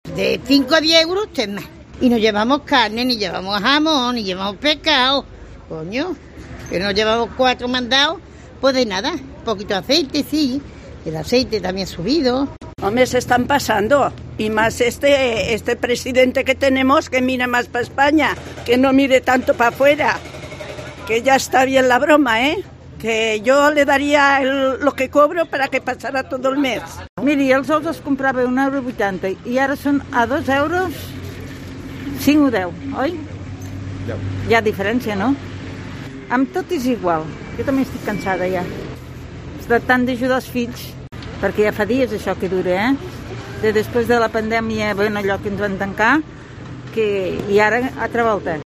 a la salida de una tienda de alimentación critican la subida de precios